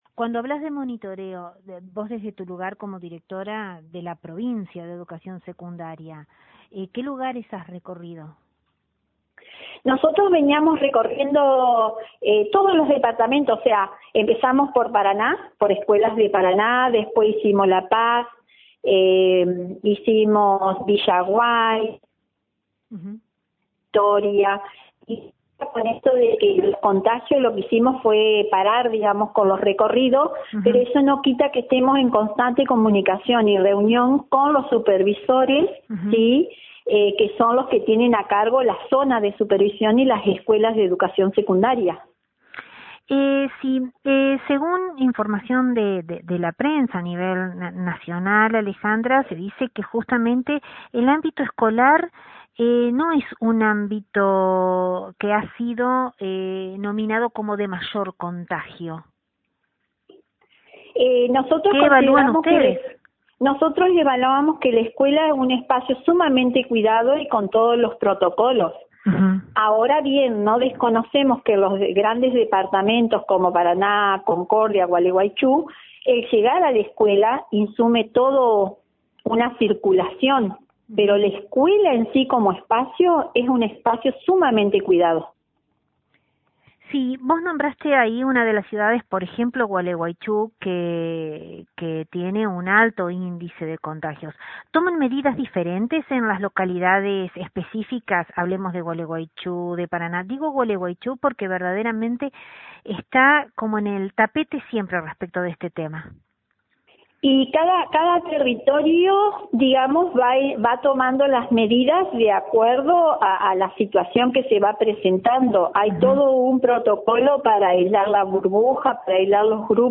Sobre la modalidad mixta de escolaridad hablamos con la Directora de Nivel Secundario de la provincia